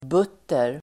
Ladda ner uttalet
Uttal: [b'ut:er]